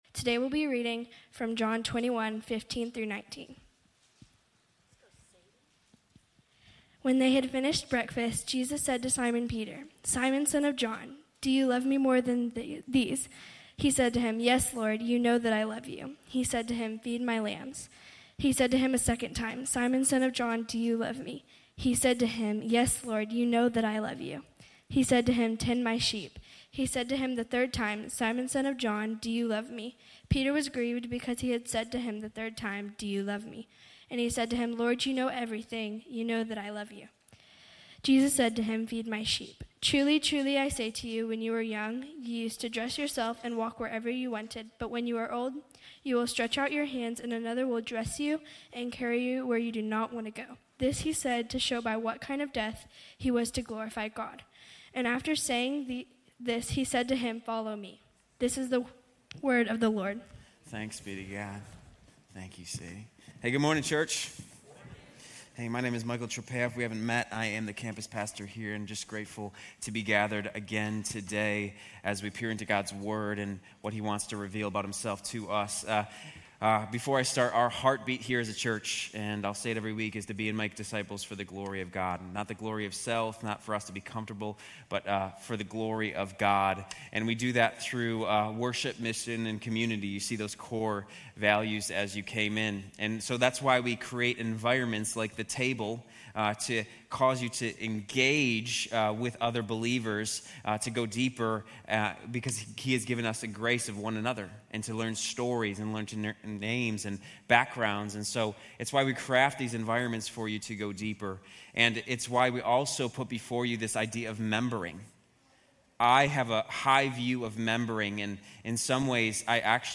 Grace Community Church University Blvd Campus Sermons 5_18 University Blvd Campus May 19 2025 | 00:33:41 1x 00:00 / 00:33:41 Subscribe Share RSS Feed Share Link Embed